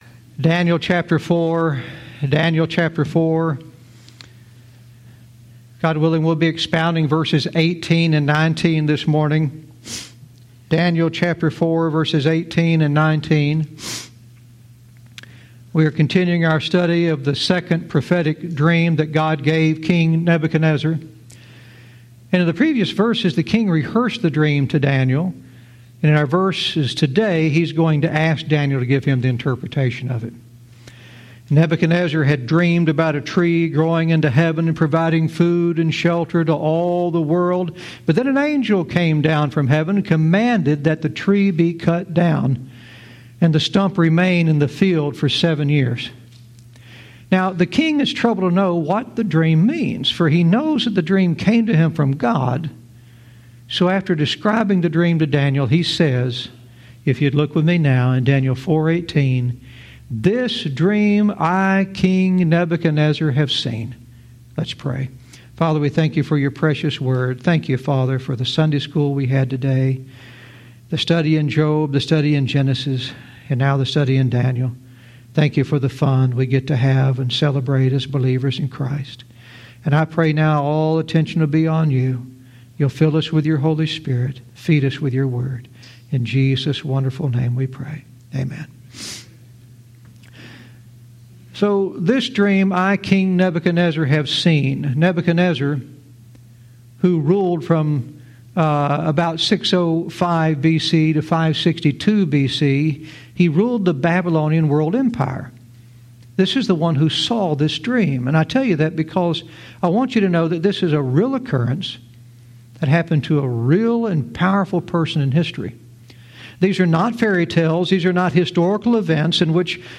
Verse by verse teaching - Daniel 4:18-19 "Interpreting the Mystery"